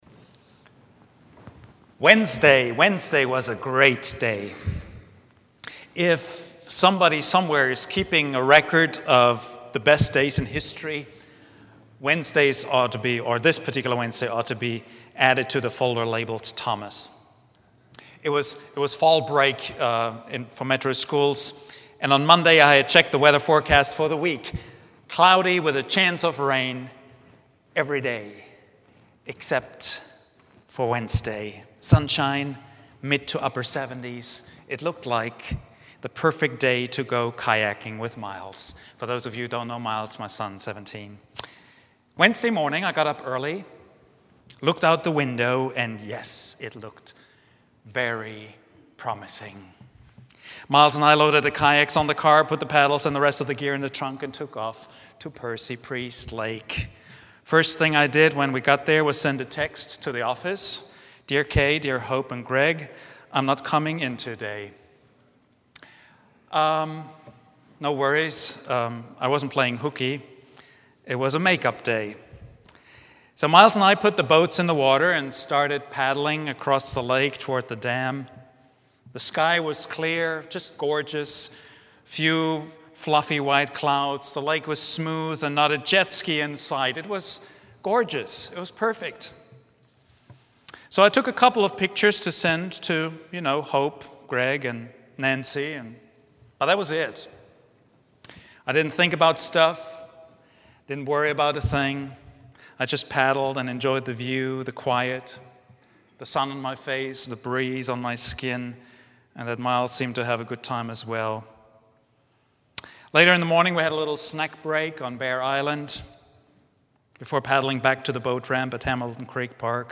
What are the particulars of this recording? preached at Vine Street Christain Church